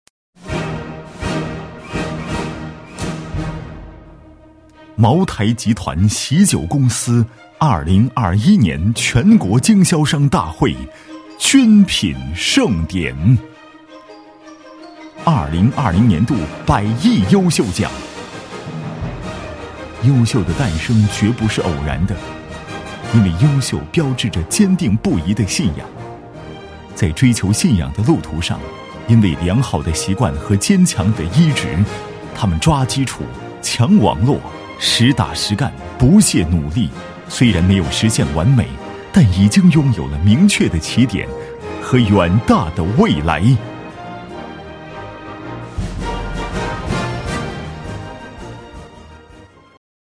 毕业于中国传媒大学播音主持专业，从事配音行业数年，普通话一级甲等水平，全能型风格加之高端的品质，让他的声音得到观众和业内的认可。